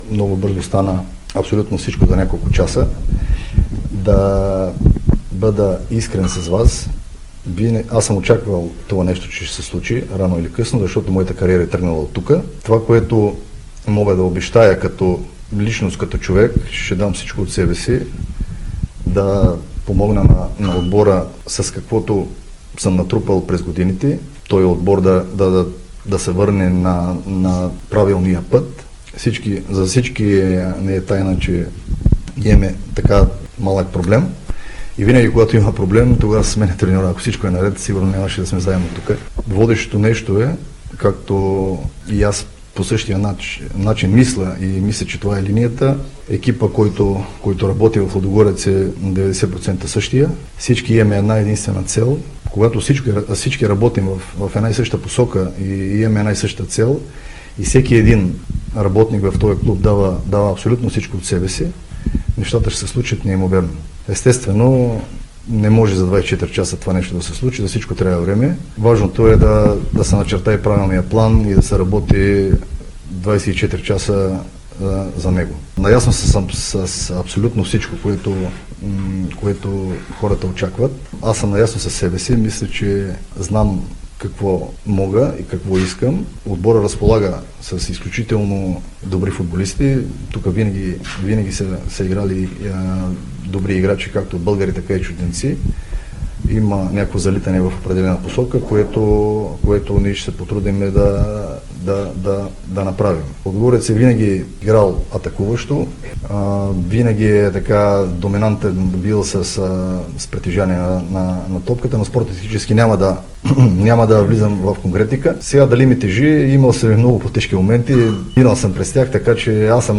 Лудогорец официално представи новия си старши треньор Ивайло Петев. Наставникът на „орлите“ ще изведе отбора в предстоящото домакинство срещу Черно море в двубой от 23-ия кръг в efbet Лига на „Хювефарма Арена“.